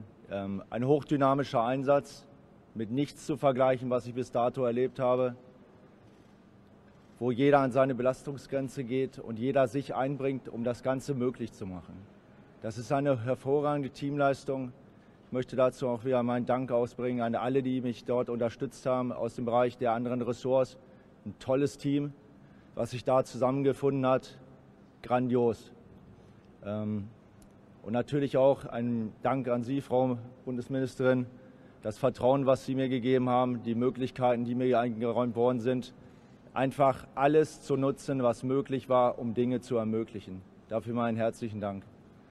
Statement des Brigadegenerals in Wunstorf